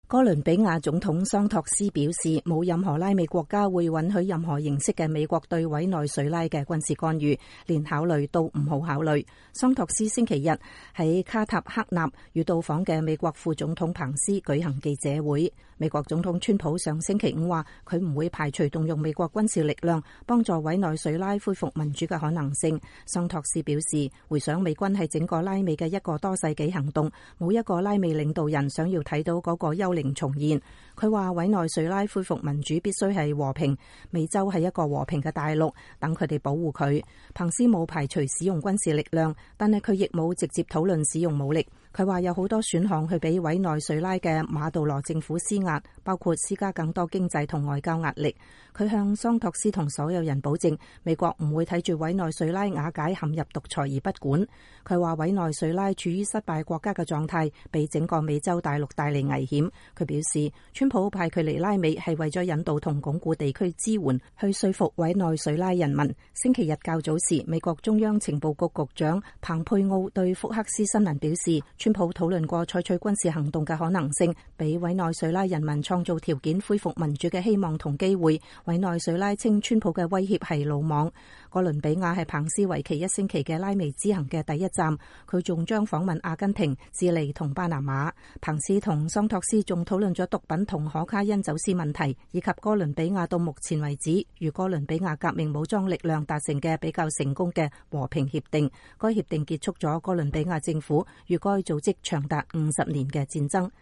哥倫比亞總統桑托斯表示，沒有任何拉美國家會允許任何形式的美國對委內瑞拉的軍事干預，連考慮都不要考慮。桑托斯星期日在卡塔赫納與到訪的美國副總統彭斯舉行記者會。